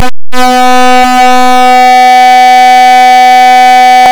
252Hz - das müsste man doch hören können....